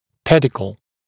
[‘pedɪkl][‘пэдикл]питающая ножка (трансплантата)